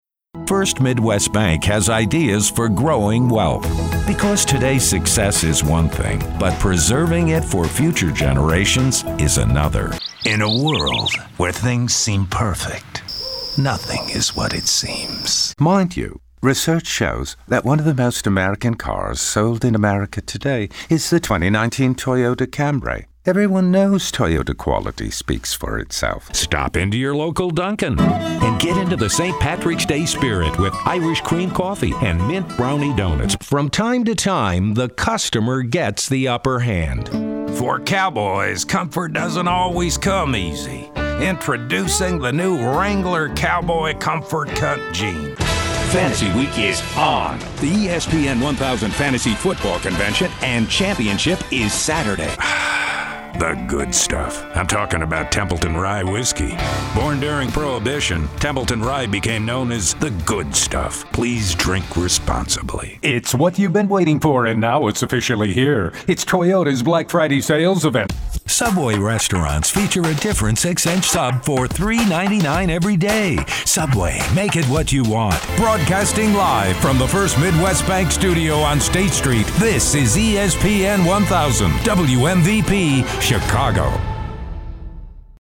I do several accents, including British, Swedish, Russian, Italian, German, Southern American, ‘Chicago’ and East Indian.
Voiceover Compilation